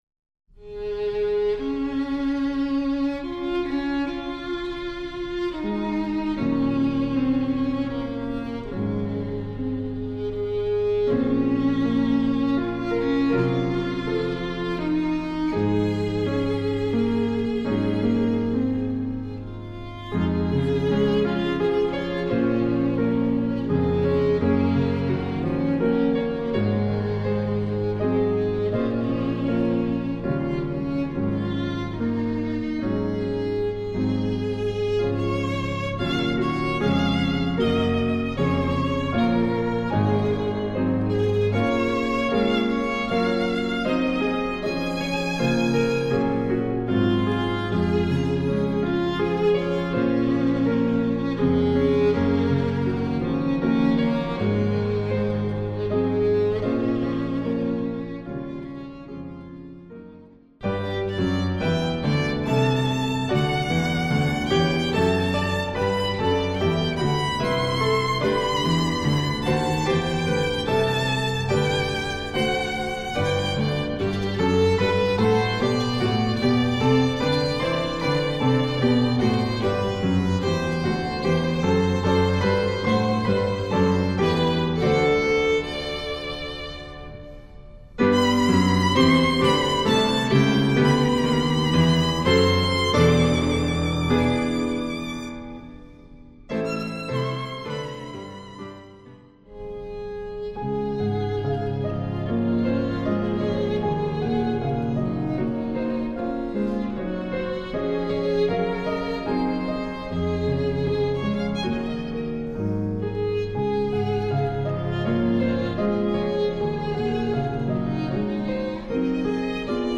Voicing: Violin W/k